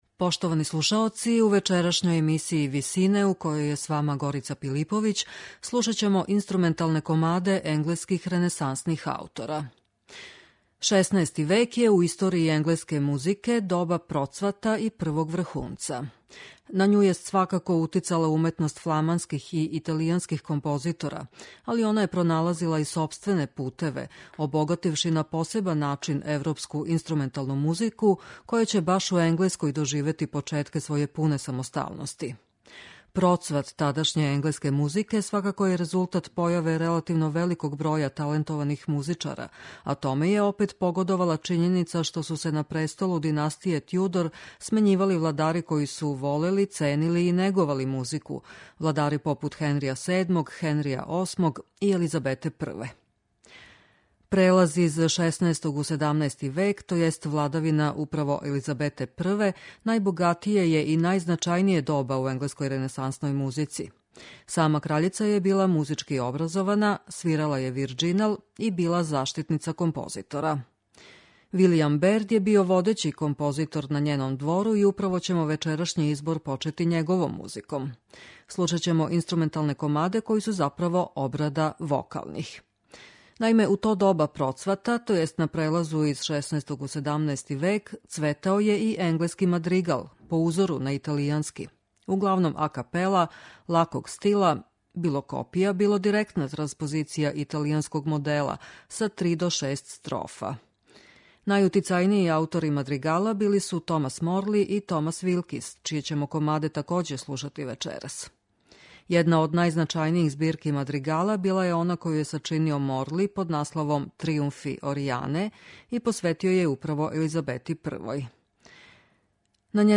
инструменталне комаде енглеских ренесансних аутора
RENESANSNI INSTRUMENTALNI KOMADI.mp3